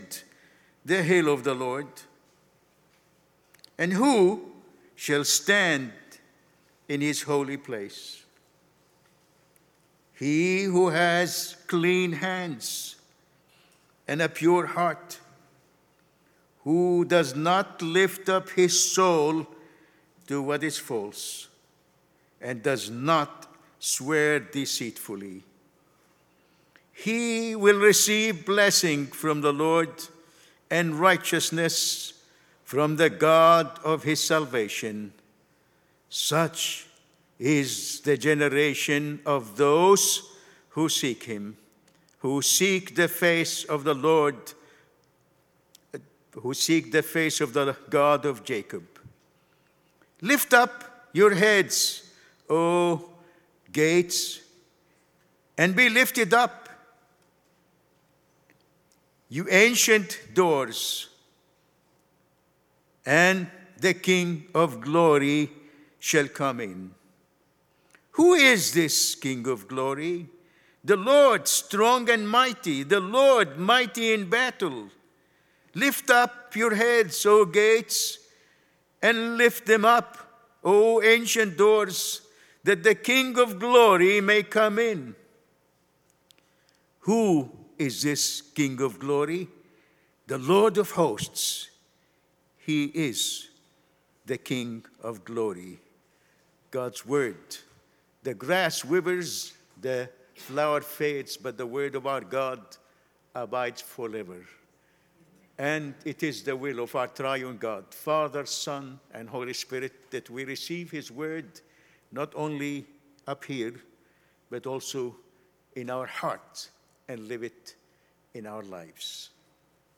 Sermons | New Life Presbyterian Church of La Mesa
(NOTE: the mp3 audio file starts a little late into the reading of Scripture).